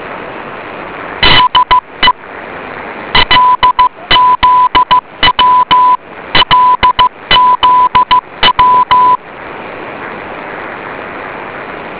sea beacons